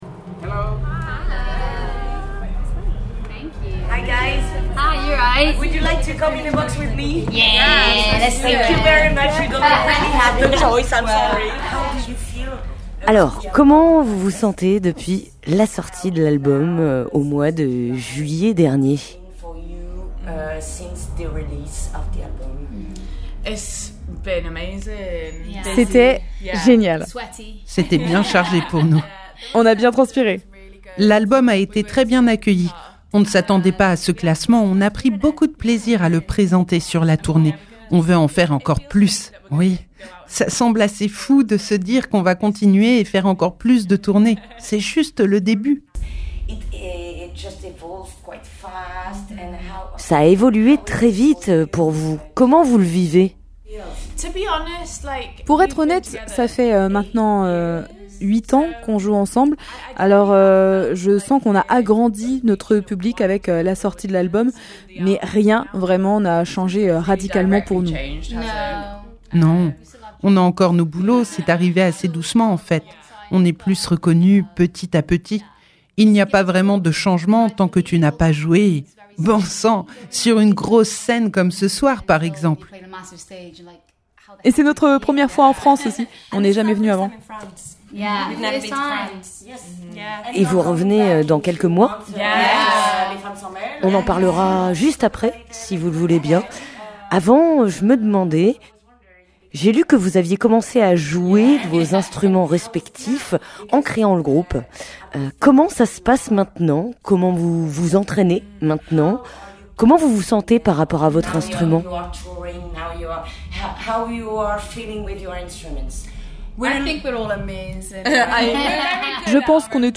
🎧 Panic Shack - On les a croisé·es sur le Cabaret Vert 2025
Tout droit venues de Cardiff, les Panic Shack célébraient au Cabaret Vert leur première date en France de leur carrière. Au micro de Radio Primitive, elles parlent de la sortie de leur premier album au mois de juillet dernier, d'être des femmes dans l'industrie musicale et du fait de ne pas encore vivre de leur musique.